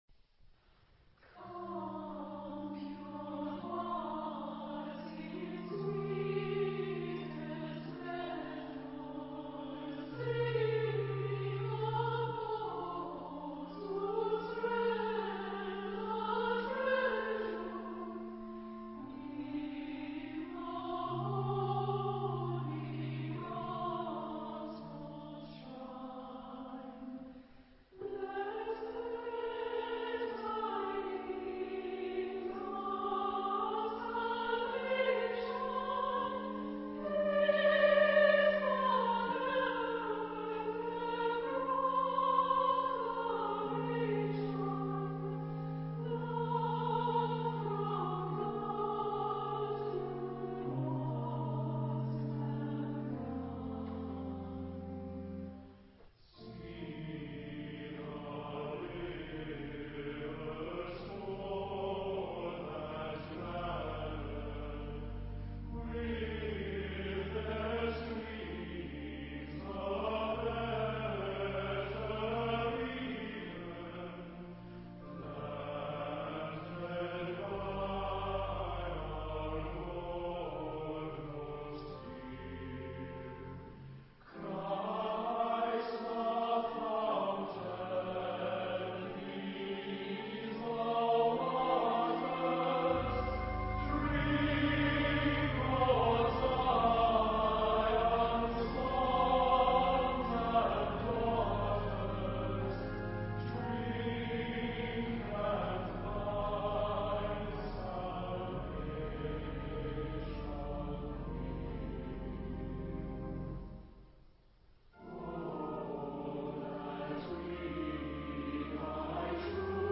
Género/Estilo/Forma: Sagrado
Instrumentación: Teclado  (1 partes instrumentales)
Instrumentos: ?rgano (1) o piano (1)
Tonalidad : si bemol mayor